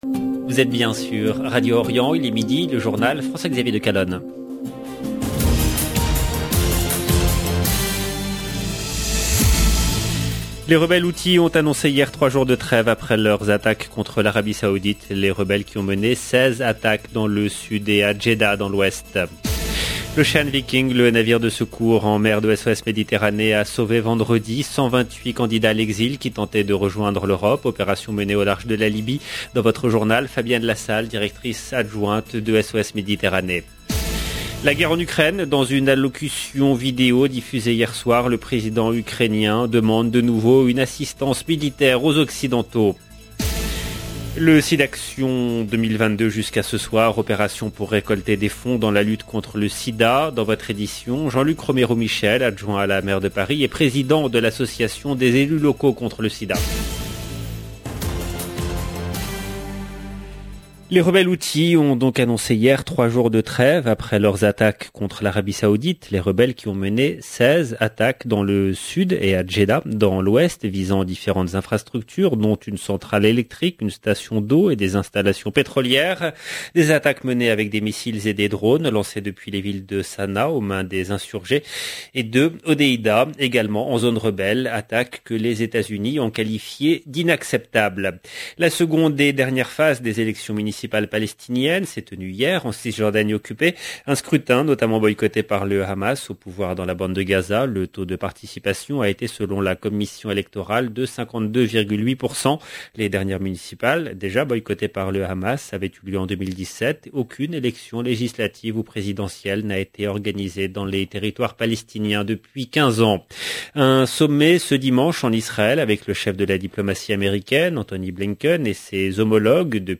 LE JOURNAL DE 12H EN LANGUE FRANCAISE DU 27/3/2022